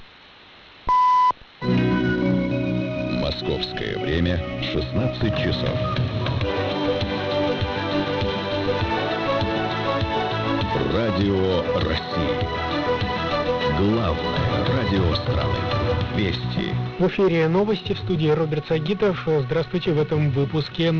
Приём на СВ Радио России 549 КГц
Приём на SDR Приёмник Финляндии